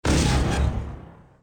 dock.ogg